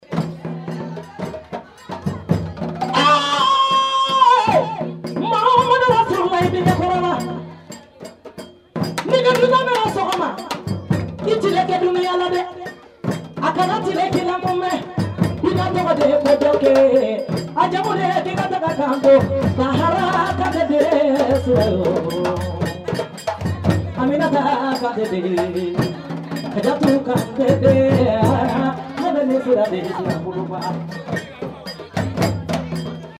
Kandia Kouyaté auf einer Hochzeit in Bamako